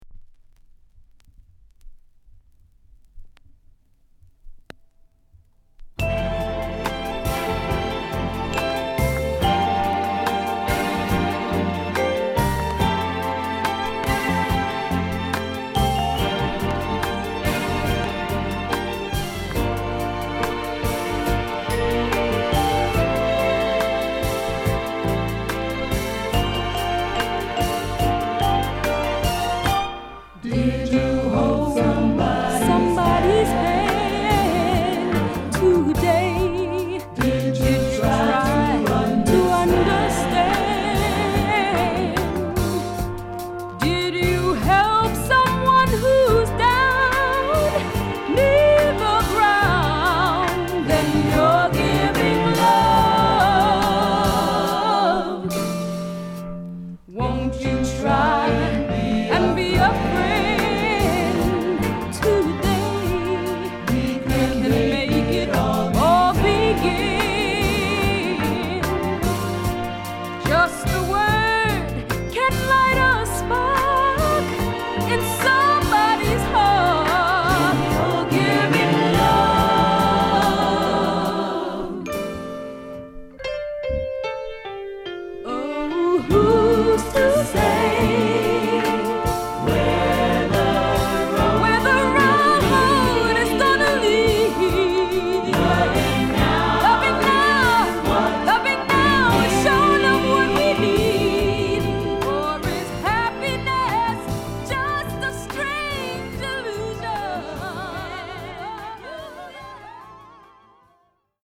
ハーレムで結成された総勢20人の若者たちから成るゴスペルグループ
一転してメロウな